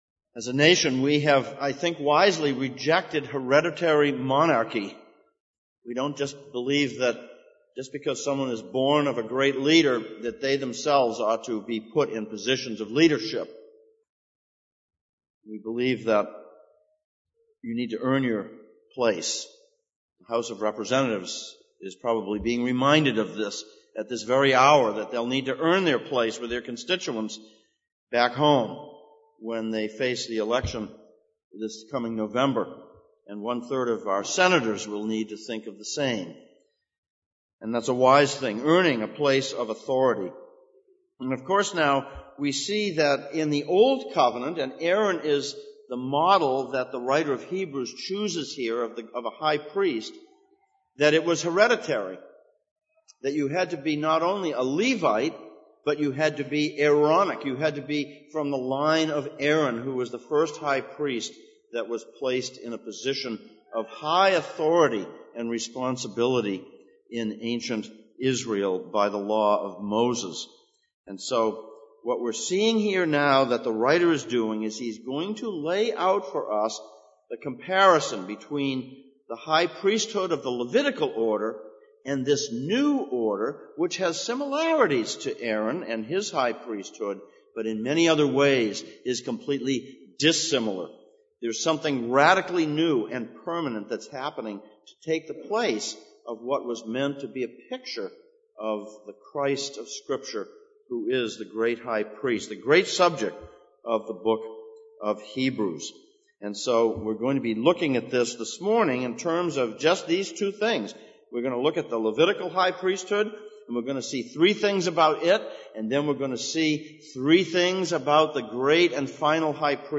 Passage: Hebrews 5:1-10, Psalm 110:1-7 Service Type: Sunday Morning